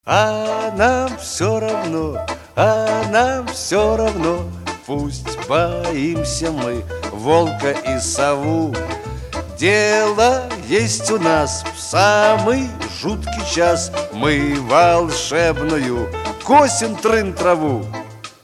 Рингтоны » Саундтреки